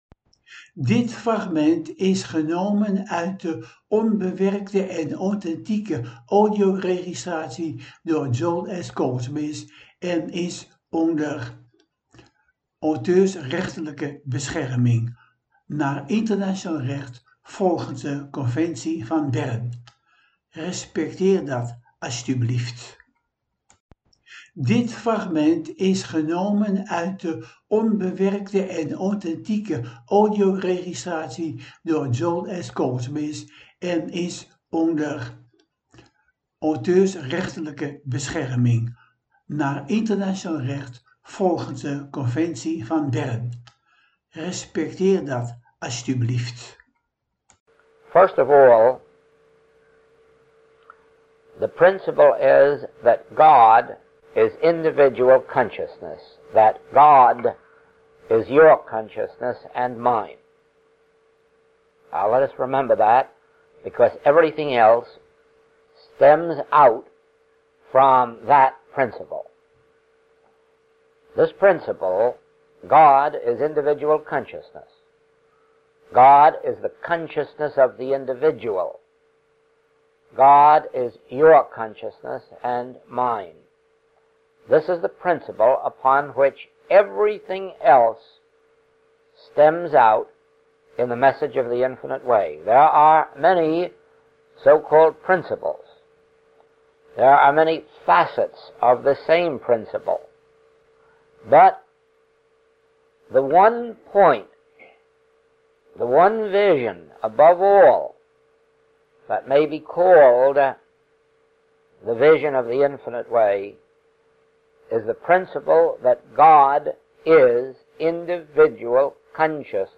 ENKELE FRAGMENTEN UIT DE AUTHENTIEKE, ONBEWERKTE AUDIOREGISTRATIES